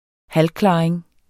Udtale [ ˈhalˌklɑˀeŋ ]